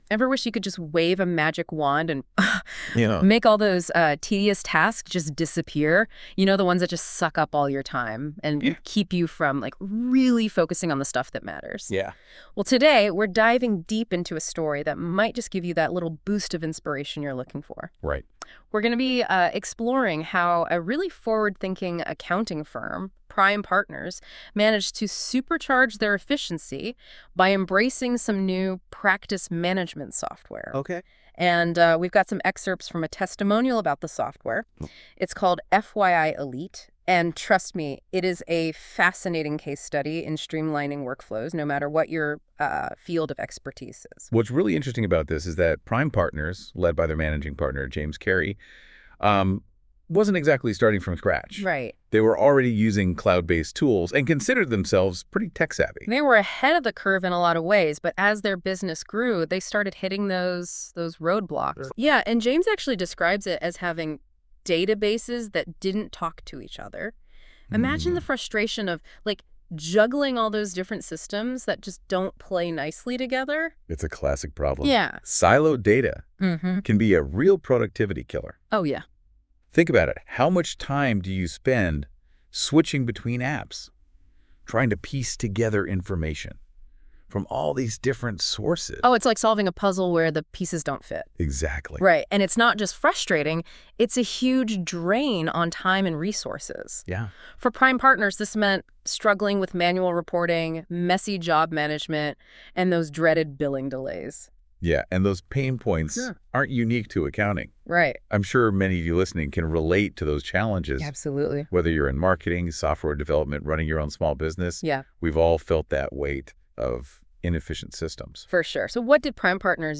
Check out the audio version of this case study, created using advanced AI technology.